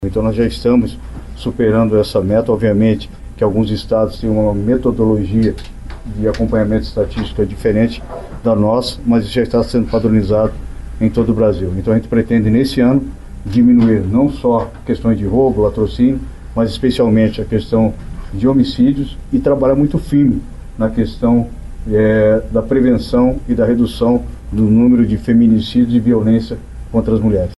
Hudson Teixeira aponta que a quantidade é menor que a meta estipulada no Brasil para o ano de 2030, que é de uma taxa de 16 homicídios para cada 100 mil habitantes.